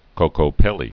(kōkō-pĕlē)